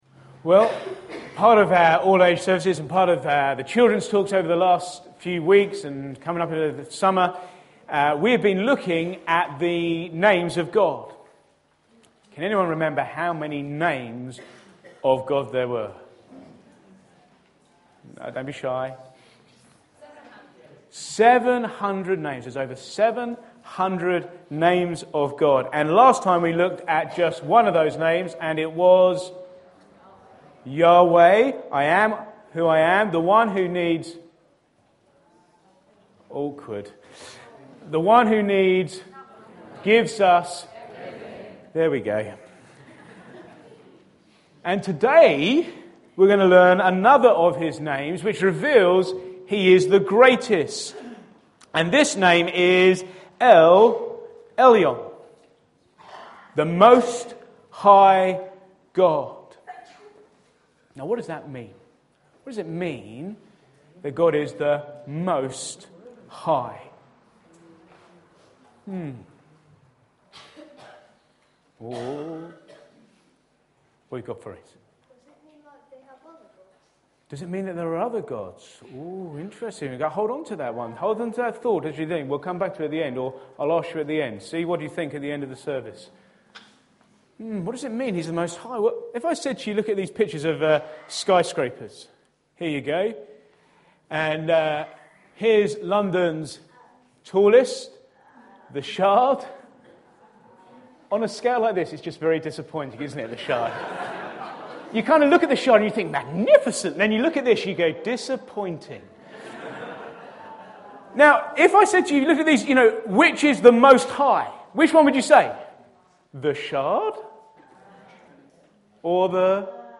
Back to Sermons The Name of God